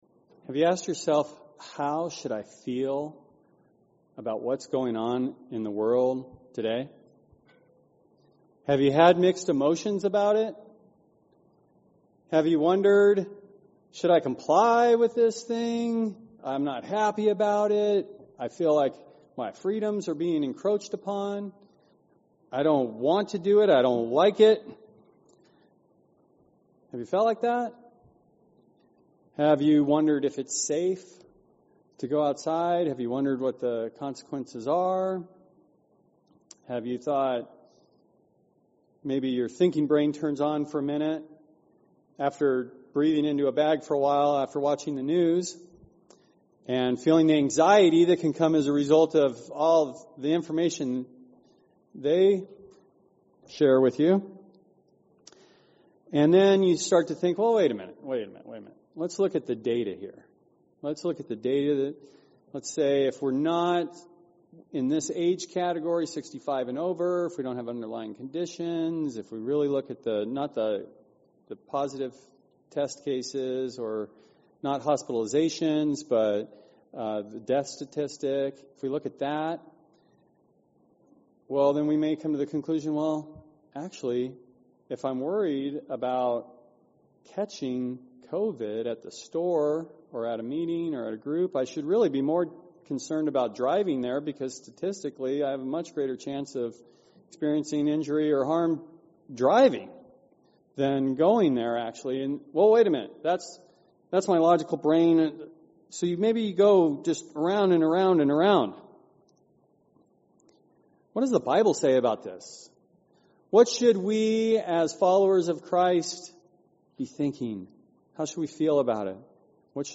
Sermons
Given in Phoenix Northwest, AZ